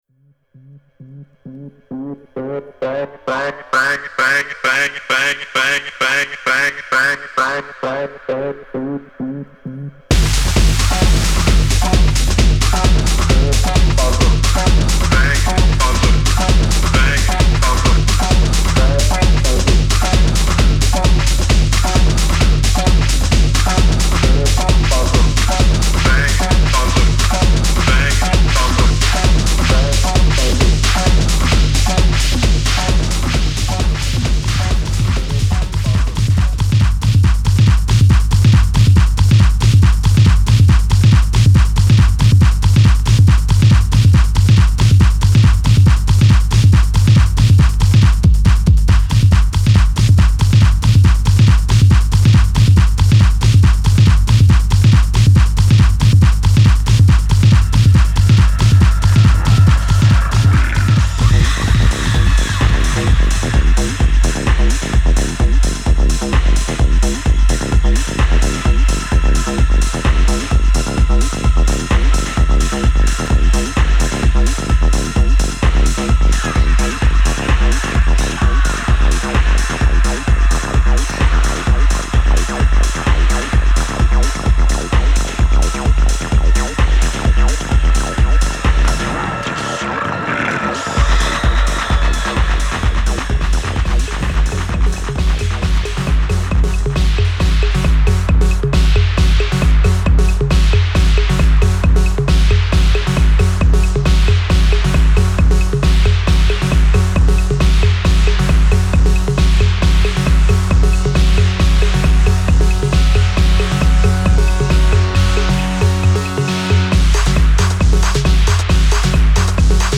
In: acid, collection, techno
Also in the music, really fat stuff.
I love this dirty and freaky sound.